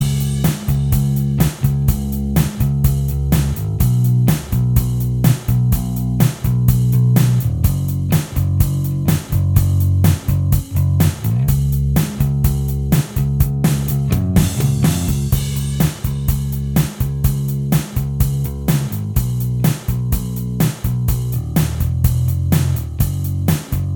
Minus Guitars Rock 5:52 Buy £1.50